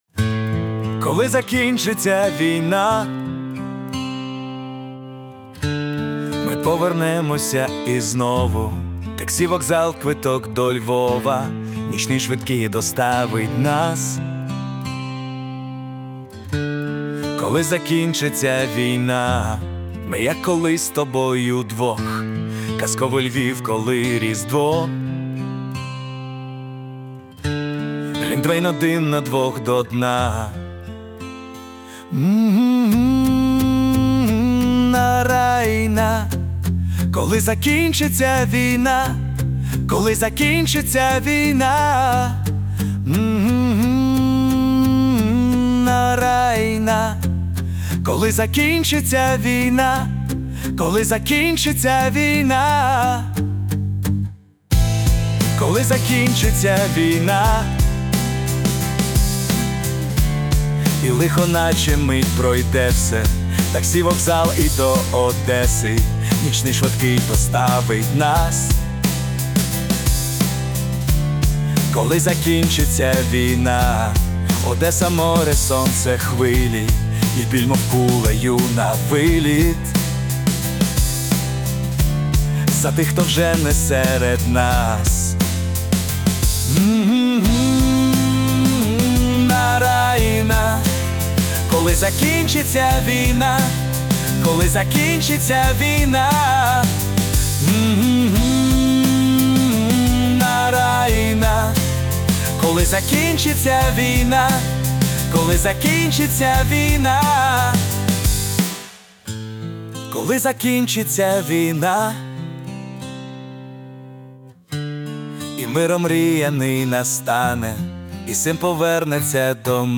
СТИЛЬОВІ ЖАНРИ: Ліричний
ВИД ТВОРУ: Пісня